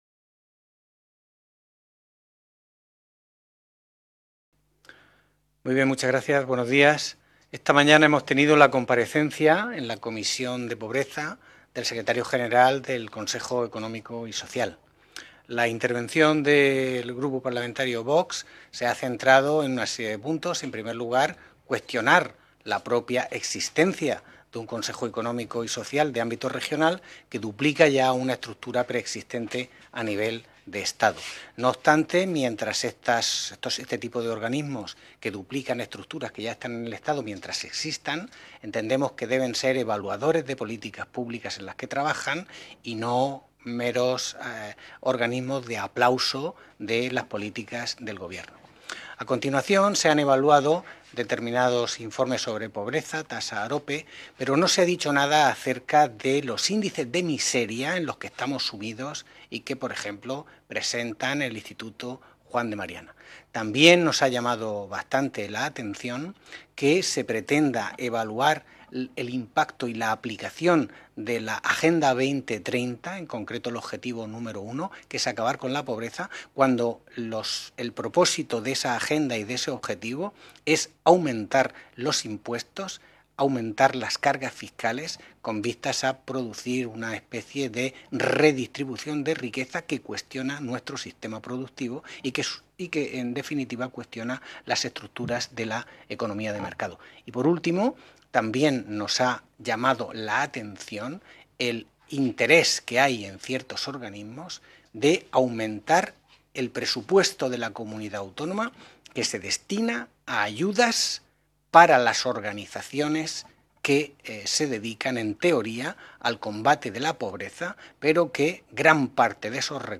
Ruedas de prensa tras la Comisión Especial de Estudio de Lucha contra la Pobreza y la Exclusión Social en la Región de Murcia
• Grupo Parlamentario Socialista
• Grupo Parlamentario Vox